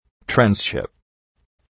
transship.mp3